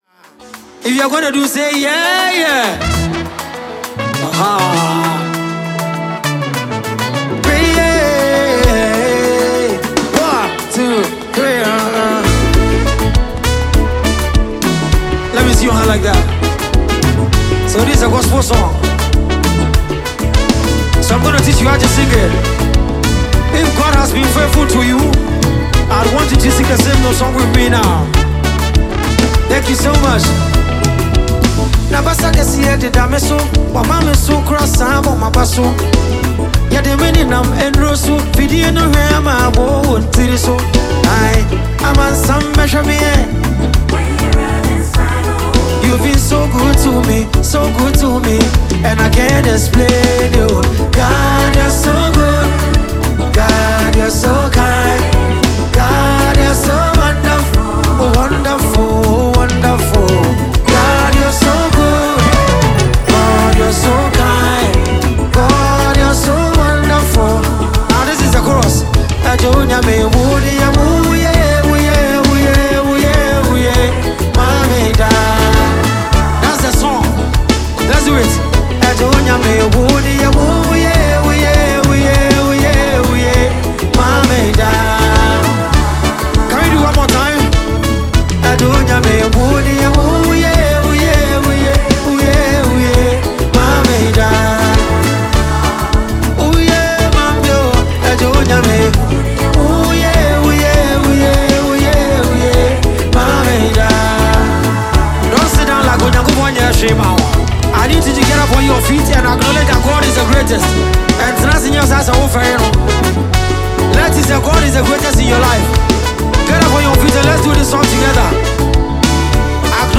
deeply emotional and soulful highlife single
Known for his lyrical depth and rich vocal delivery
soothing voice